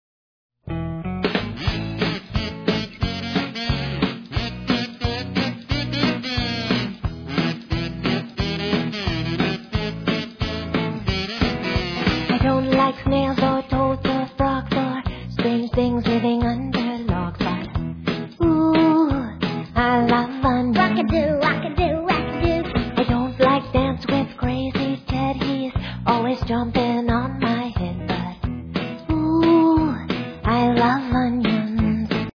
excellent rock 'n' roll with wacky skits